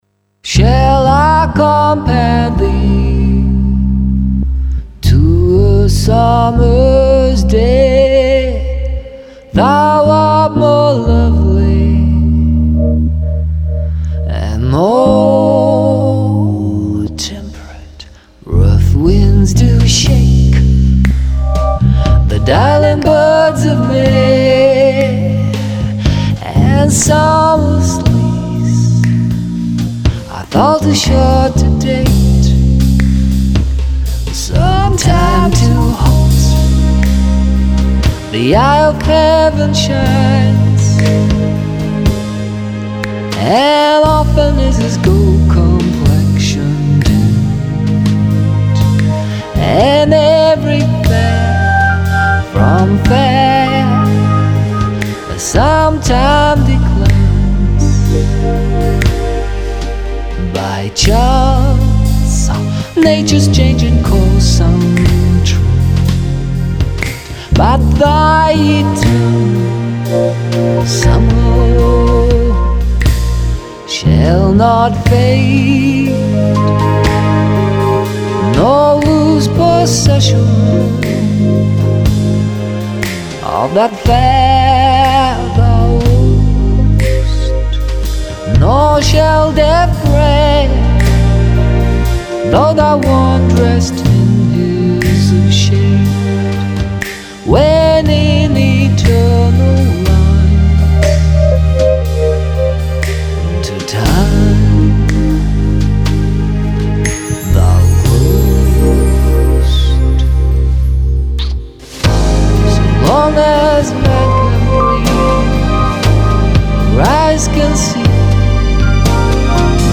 Good to alternate between emphasis and relaxation.